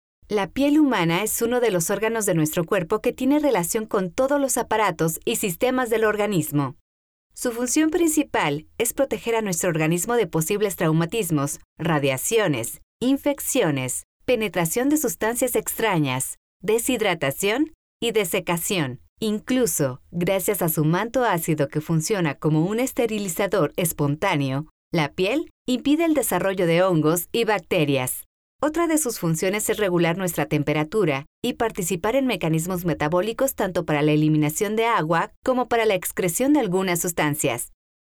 Commercial, Natural, Cool, Versatile, Corporate
Explainer